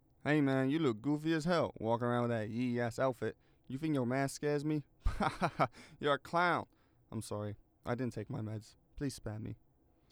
Voice Lines / Street barklines
Update Voice Overs for Amplification & Normalisation